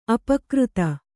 ♪ apakřta